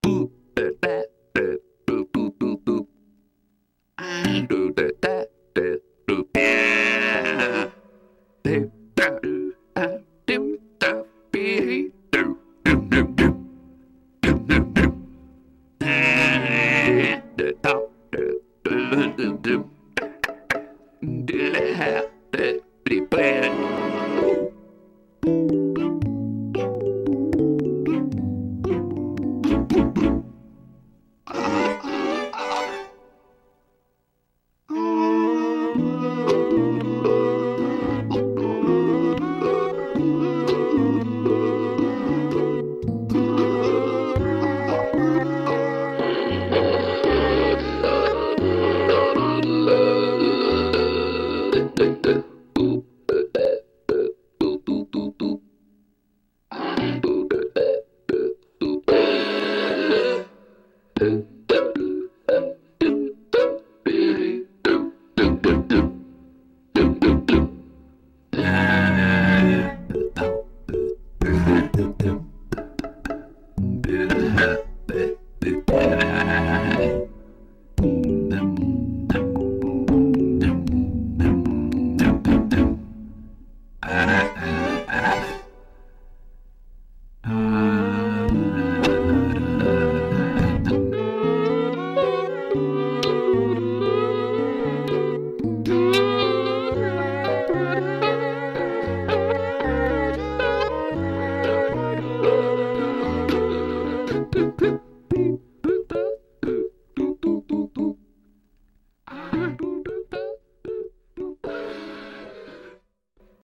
Some very very silly noises are to be found within.